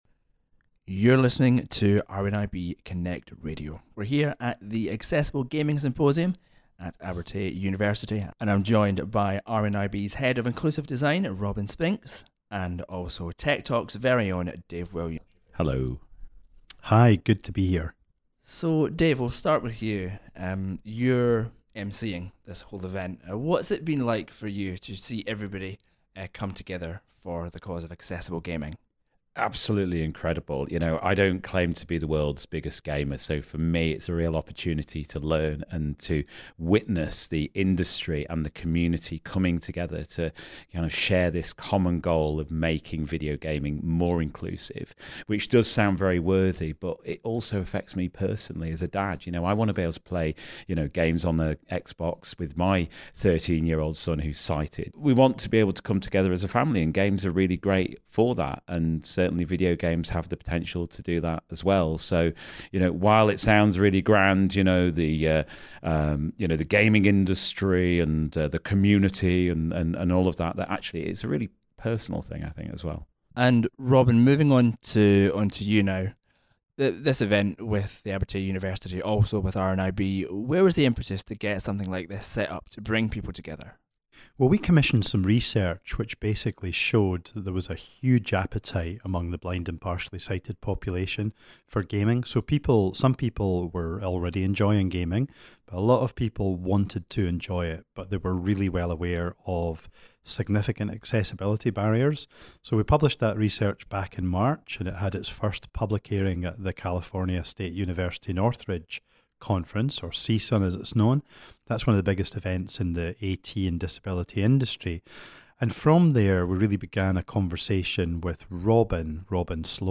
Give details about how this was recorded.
At the Accessible Gaming Symposium 2022